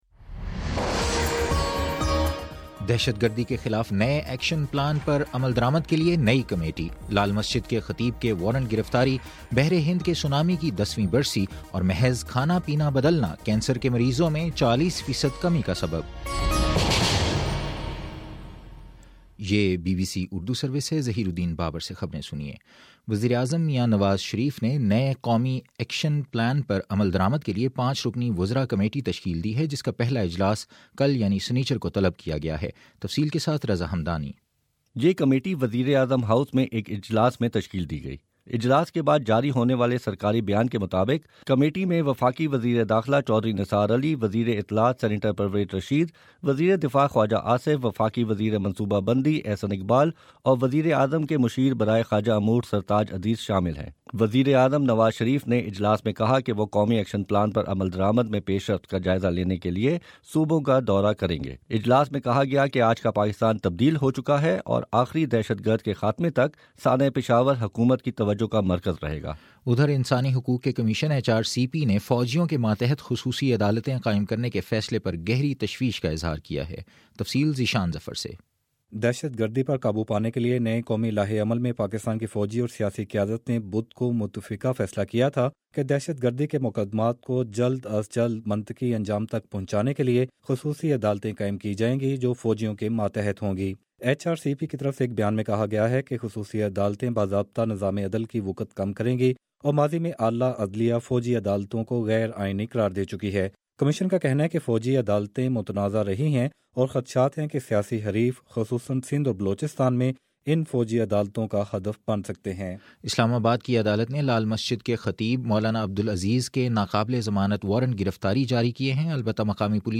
دسمبر 26 : شام سات بجے کا نیوز بُلیٹن